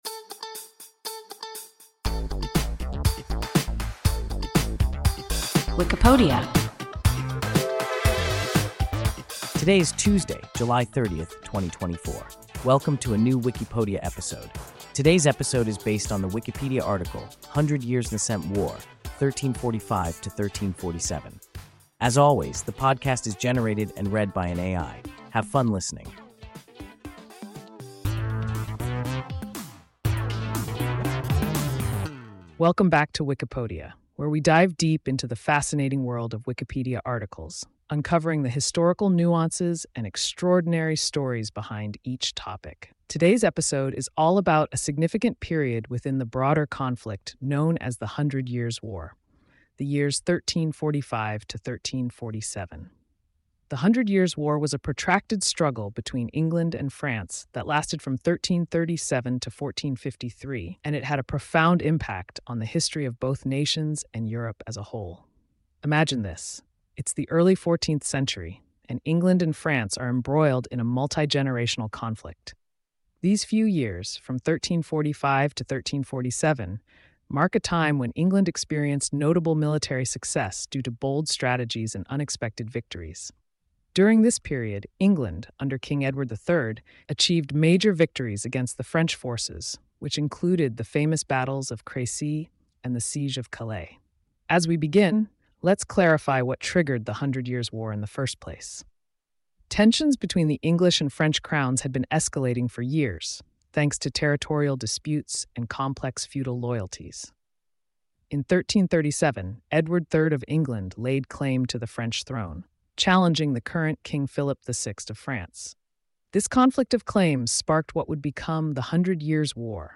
Hundred Years%27 War, 1345–1347 – WIKIPODIA – ein KI Podcast